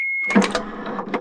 BoltsUp.ogg